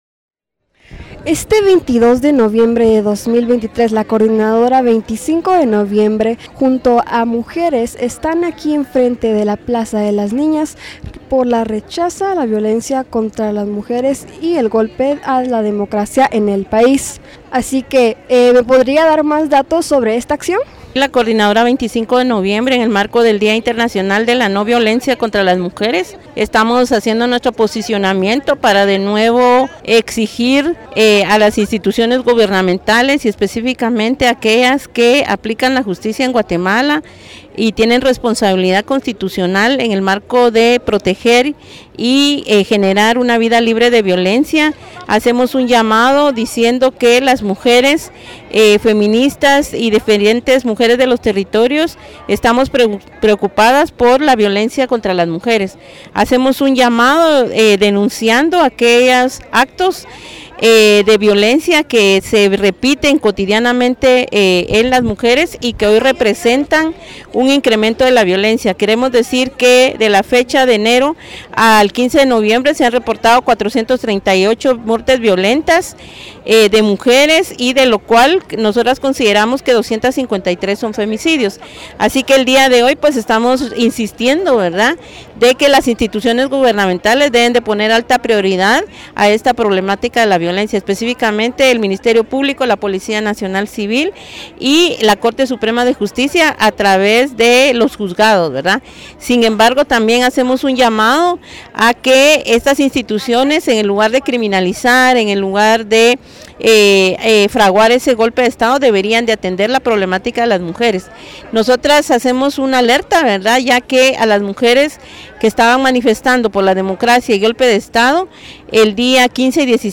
En víspera de la conmemoración del Día Internacional de la NO Violencia contra las Mujeres,  las integrantes la Coordinadora 25 noviembre, ofrecieron una conferencia de prensa en la Plaza de las Niñas, para expresar su rechazo a la violencia contra las mujeres y el golpe a la democracia que ejecutan desde el Ministerio Público-MP, el Organismo Judicial y el Congreso de la República.
Mujeres diversas de distintos territorios alzaron la voz para denunciar el debilitamiento del Estado, lo que se refleja  en la poca capacidad de las instituciones gubernamentales para garantizar que las mujeres tengan acceso a gozar de sus derechos sexuales y reproductivos, a un trabajo digno, salario justo, educación, alimentación, vivienda, la tierra y seguridad.